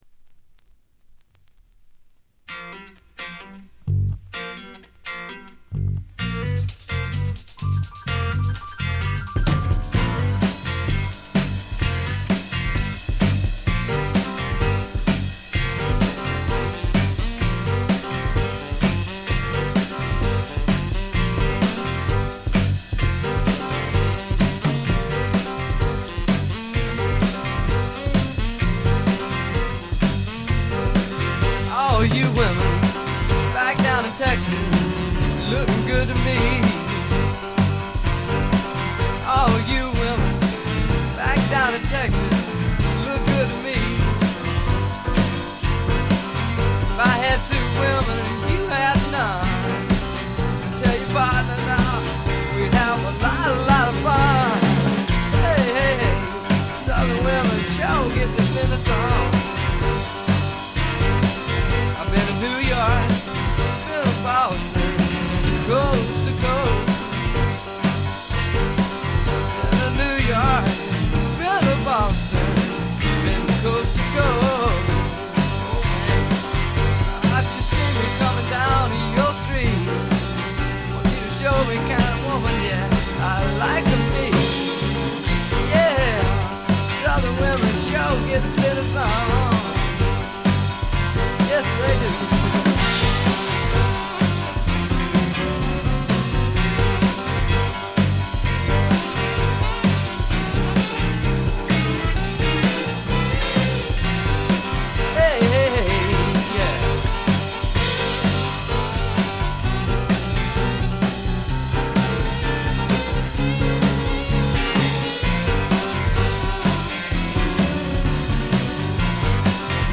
ごく微細なノイズ感のみ。
バンドサウンドとしてはこちらの方が上かな？
米国スワンプ基本中の基本！
試聴曲は現品からの取り込み音源です。